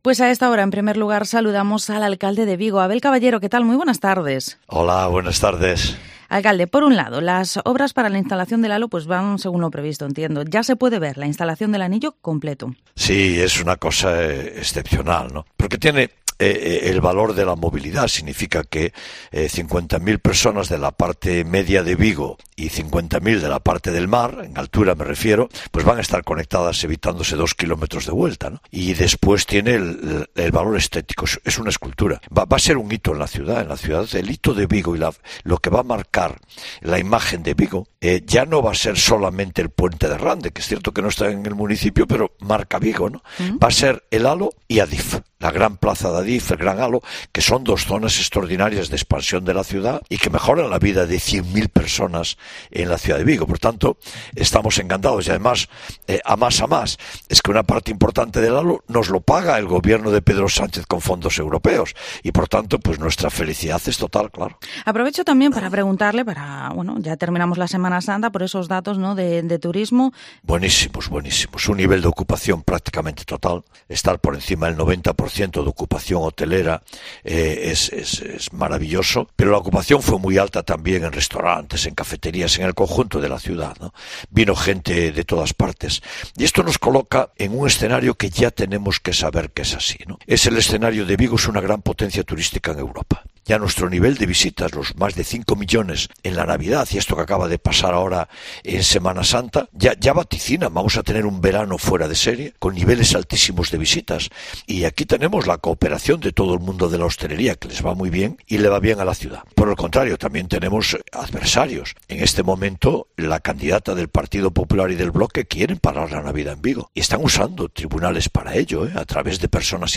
Entrevista al Alcalde de Vigo, Abel Caballero